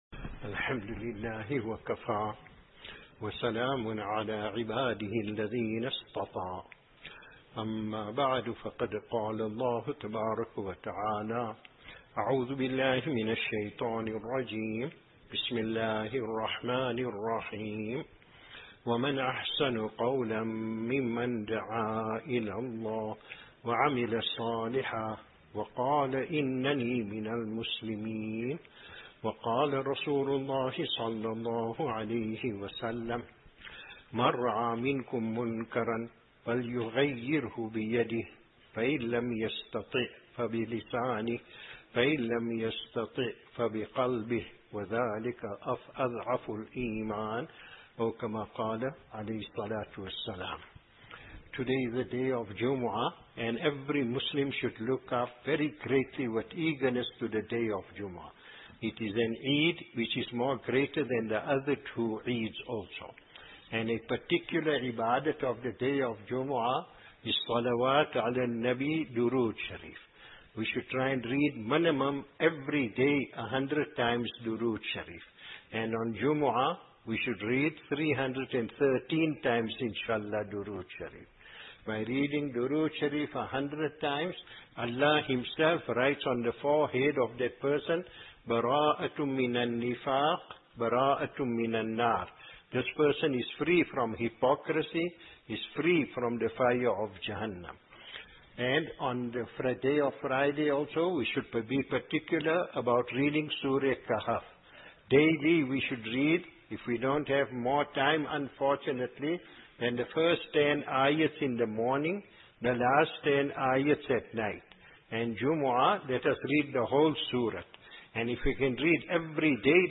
07 Shawwal 1444 / 28 April 2023 Jummah Bayaan Azaadville - The Pure Tablighi Foundation of Our Pious and The Deviation we are Facing is Due to Lack of Zikr & Association with Wrong-Doers | Khanqah Akhtari